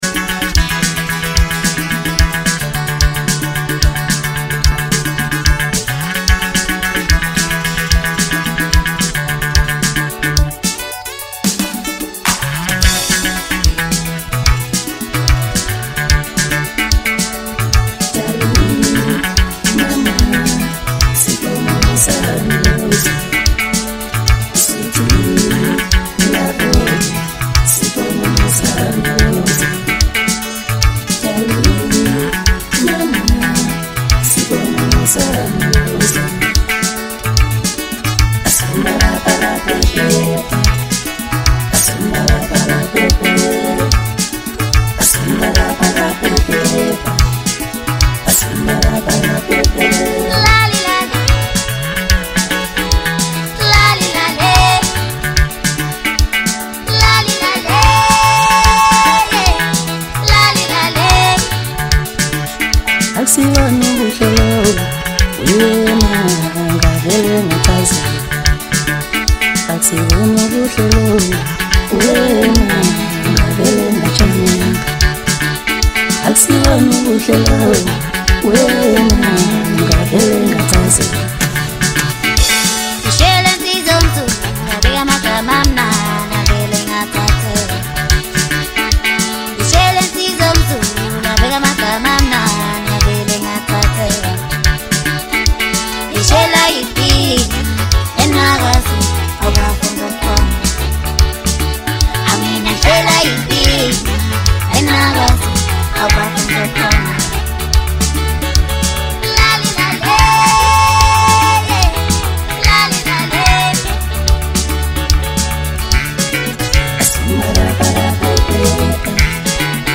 Home » Album?EP » Maskandi